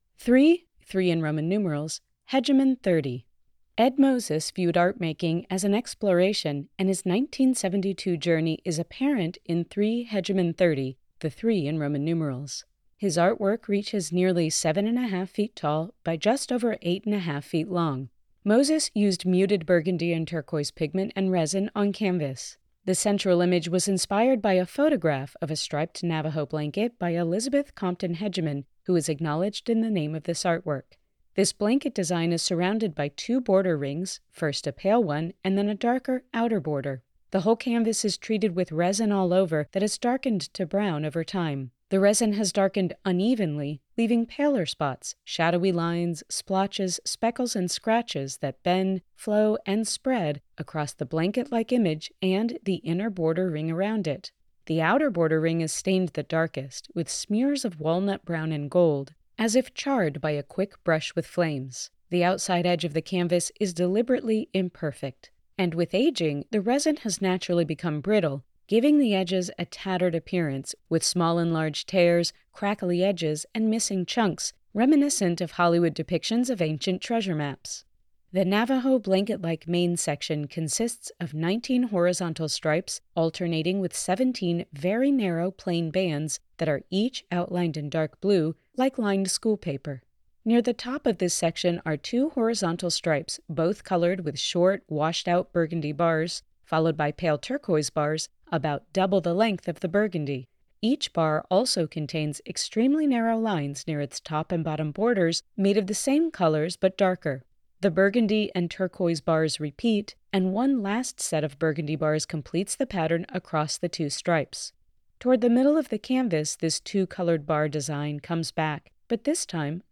Audio Description (02:31)